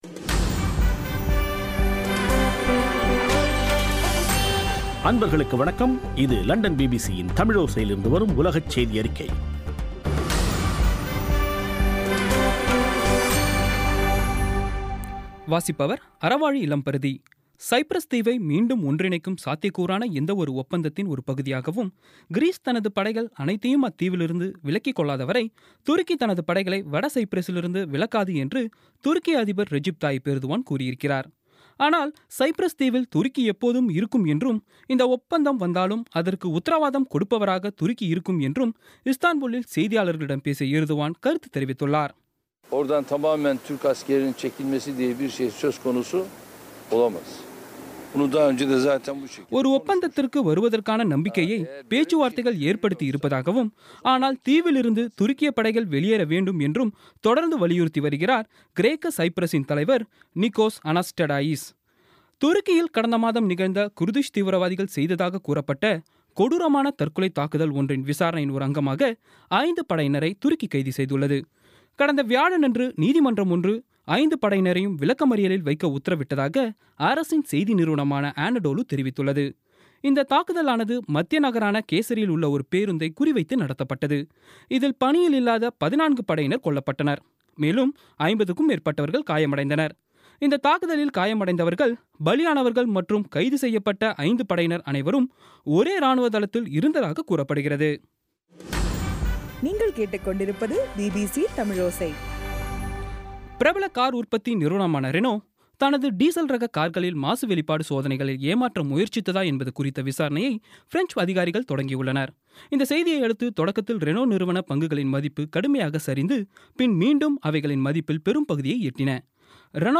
பிபிசி தமிழோசை செய்தியறிக்கை (13/01/2017)